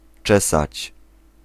Ääntäminen
Ääntäminen France: IPA: [pe.ɲe] Haettu sana löytyi näillä lähdekielillä: ranska Käännös Ääninäyte Verbit 1. czesać Määritelmät Verbit Démêler , arranger les cheveux , la barbe , avec un peigne .